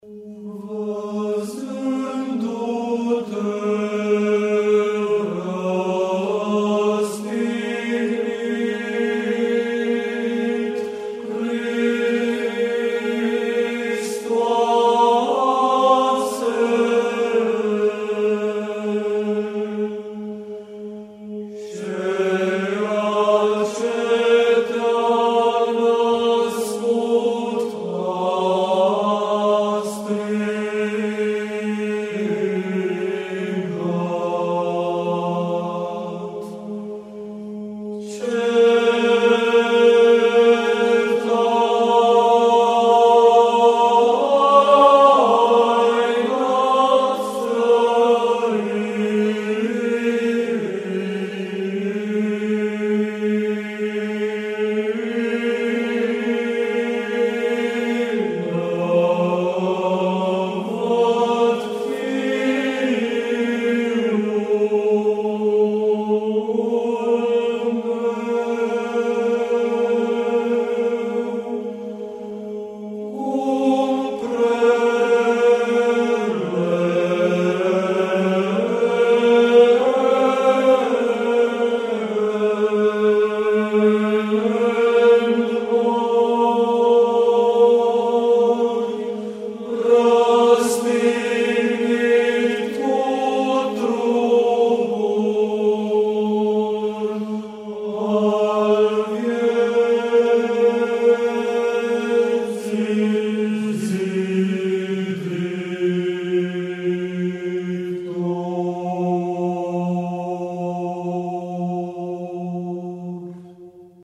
Vazindu-te Rastignit Nascatoarea - Corul Preludiu
Vazindu-te-Rastignit-Nascatoarea-Corul-Preludiu.mp3